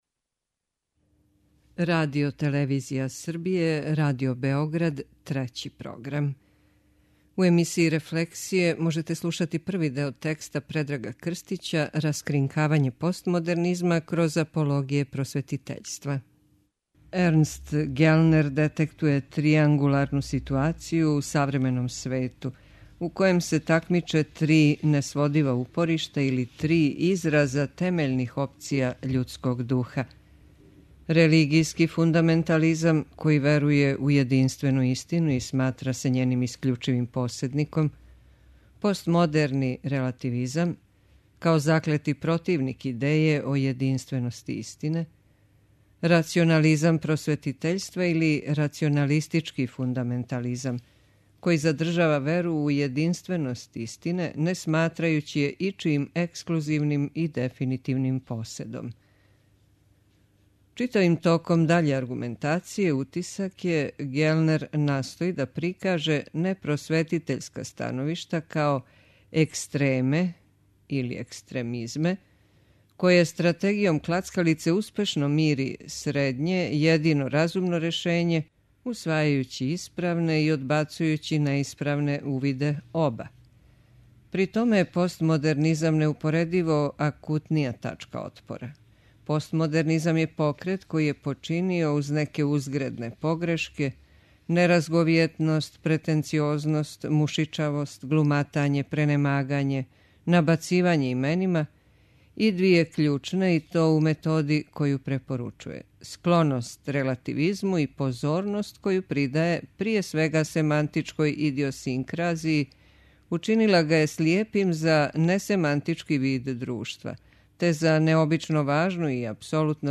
преузми : 21.15 MB Рефлексије Autor: Уредници Трећег програма У емисијама РЕФЛЕКСИЈЕ читамо есеје или научне чланке домаћих и страних аутора.